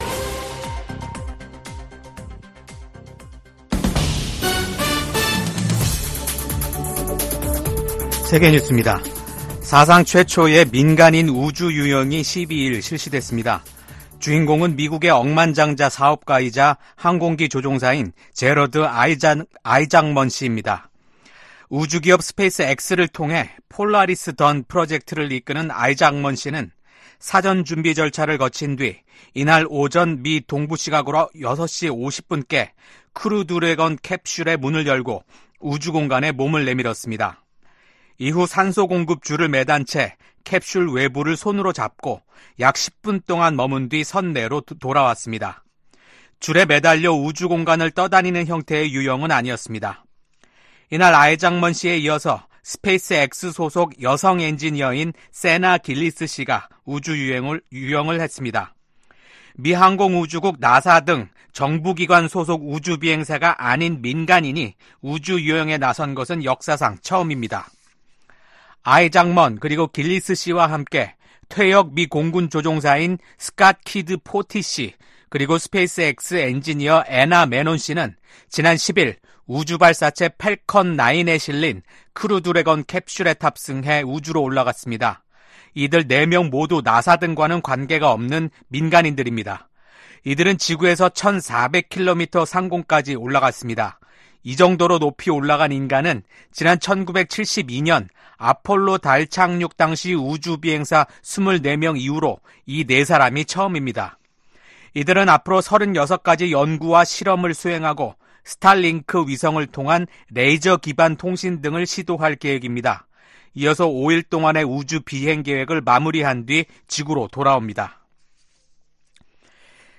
VOA 한국어 아침 뉴스 프로그램 '워싱턴 뉴스 광장' 2024년 9월 13일 방송입니다. 북한이 70여일 만에 또 다시 단거리 탄도미사일 도발에 나섰습니다.